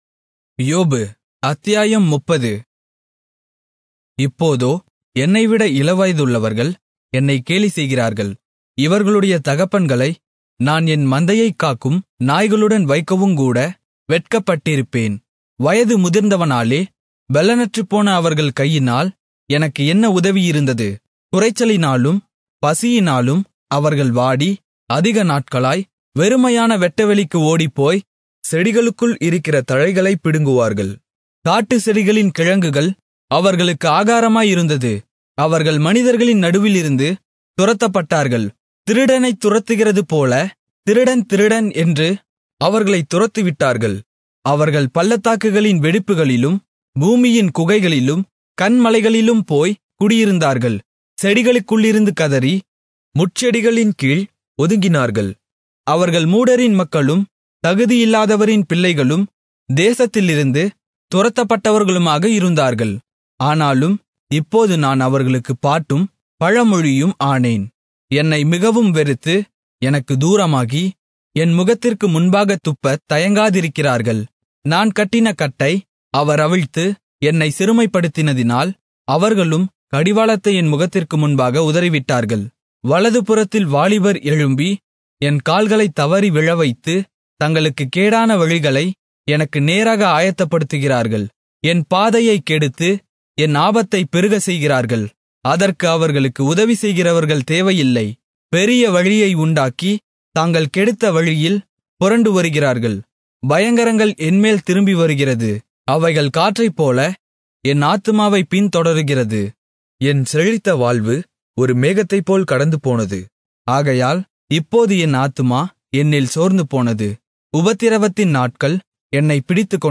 Tamil Audio Bible - Job 7 in Irvta bible version